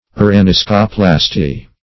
Uraniscoplasty synonyms, pronunciation, spelling and more from Free Dictionary.
Search Result for " uraniscoplasty" : The Collaborative International Dictionary of English v.0.48: Uraniscoplasty \U`ra*nis"co*plas`ty\, n. [Gr.